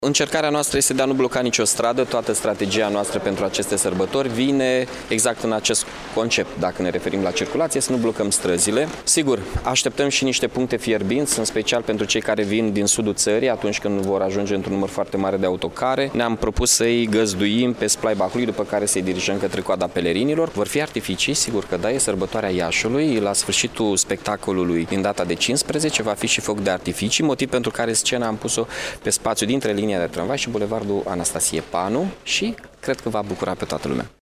Primarul municipiului, Mihai Chirica, a precizat că Târgul de Toamnă se va desfăşura pe Esplanedele de la Hala Centrală şi din Târgul Cucului, în Piaţa Unirii şi pe Pietonalele Ştefan cel Mare şi Alexandru Lăpuşneanu.